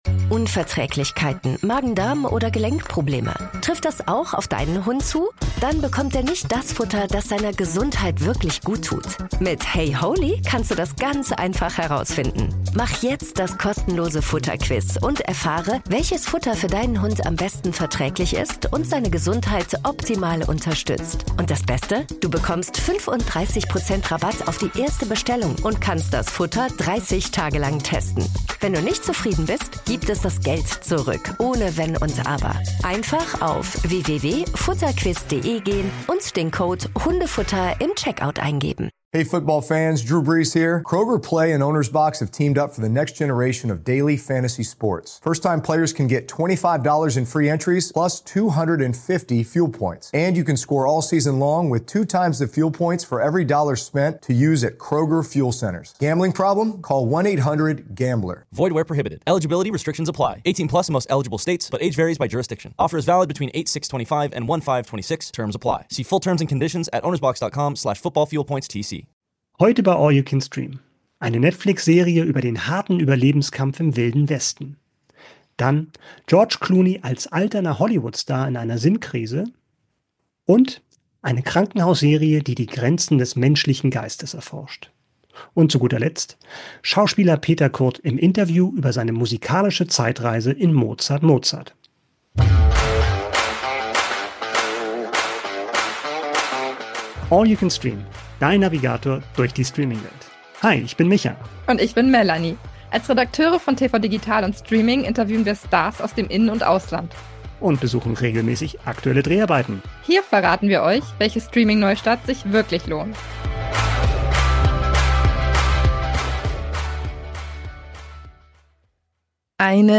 Die größten Streaming-Highlights von Anfang bis Mitte Dezember. UND: unser Interview mit Schauspieler Peter Kurth über seine Serie "Mozart/Mozart" (ARD Mediathek)